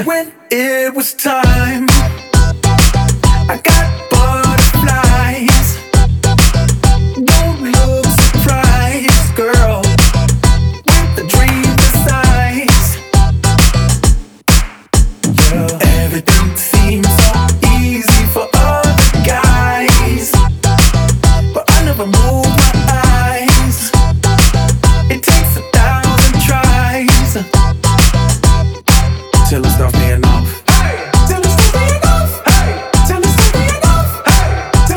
Жанр: Поп / Музыка из фильмов / Саундтреки